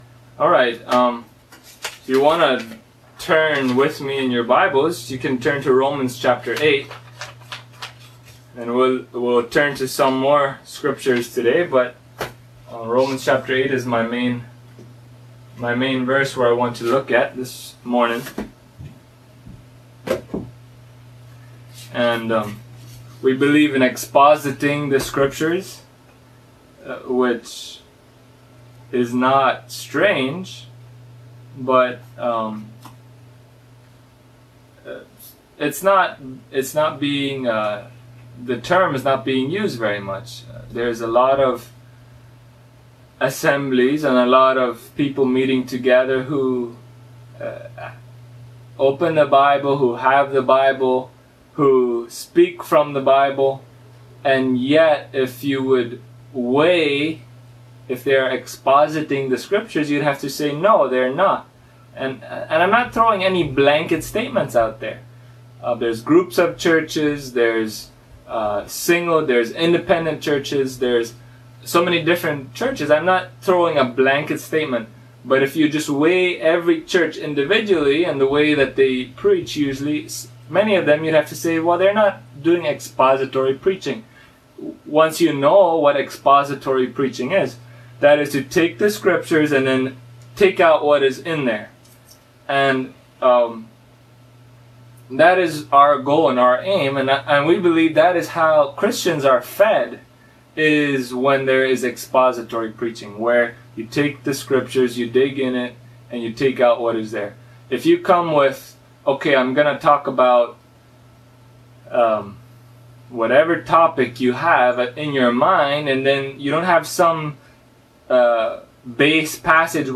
Audio is only the sermon
Romans 8:29 Service Type: Sunday Morning In the video